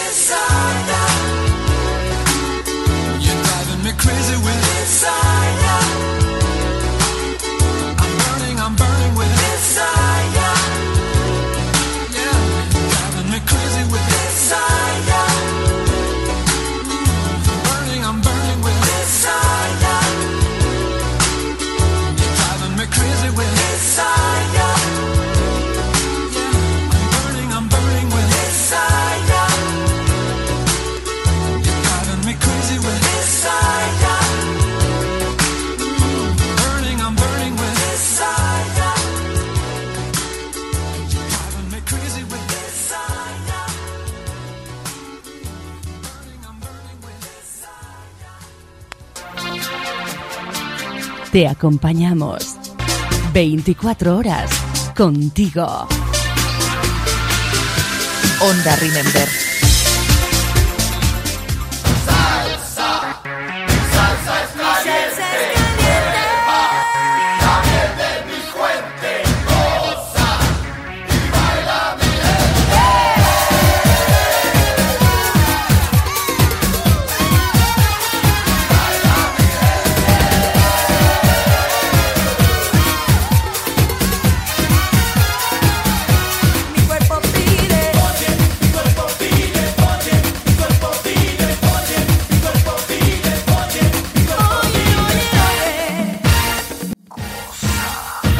Tema musical, identificació de la ràdio i tema musical.
Musical